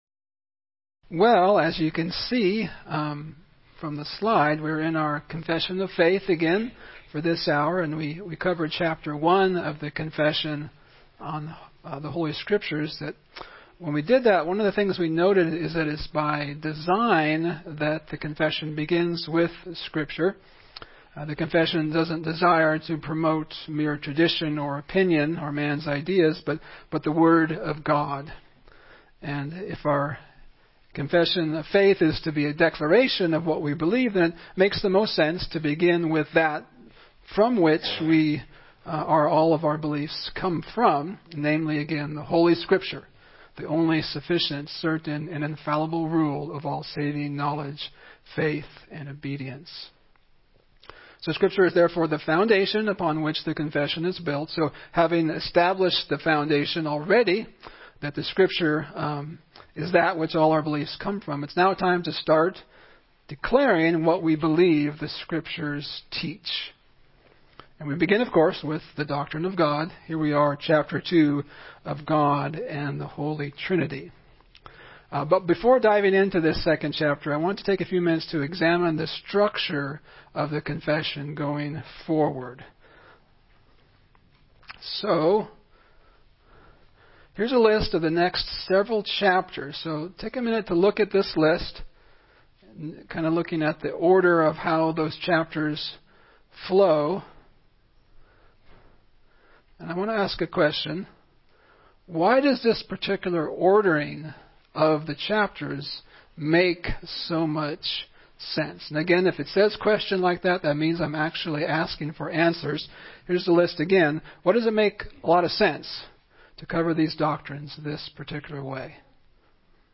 Series: 1689 Confession Essentials Service Type: Sunday School